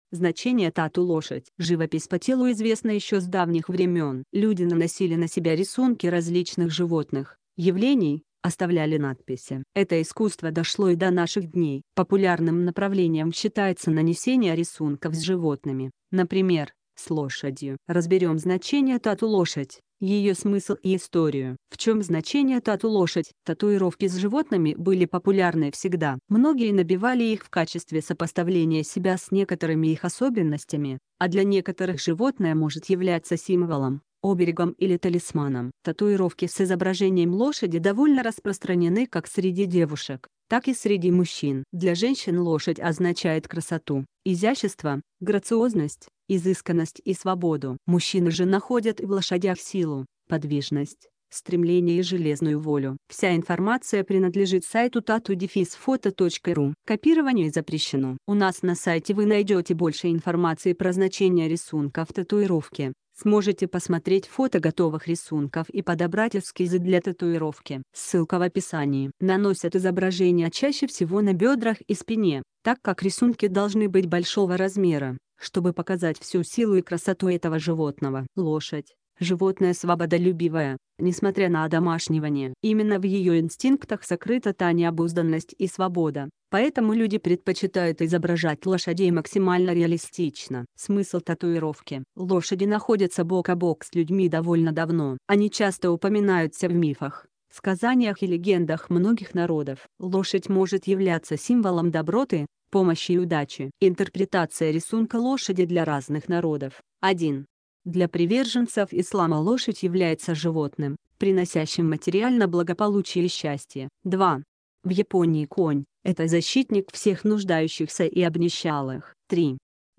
Значение-тату-лошадь-аудио-версия-статьи-для-сайта-tattoo-photo.ru_.mp3